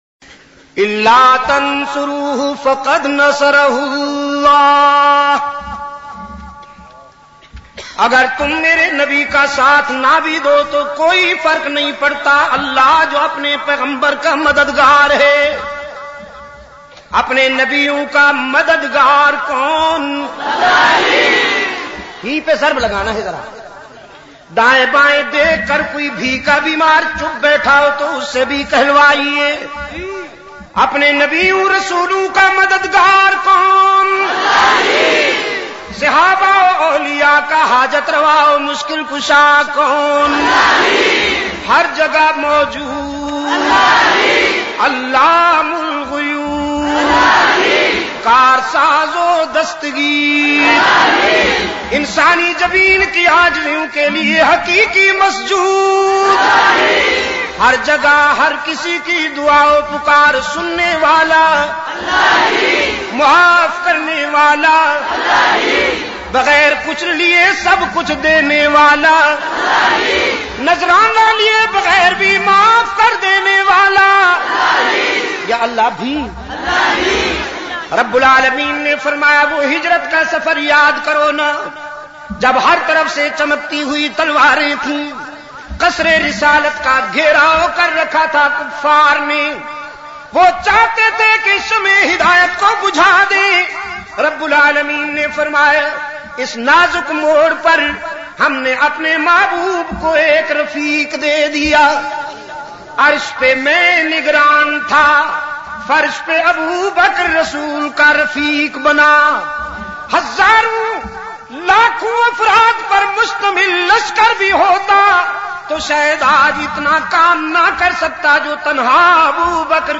Shan e Siddiq e Akbar Bayan MP3 Download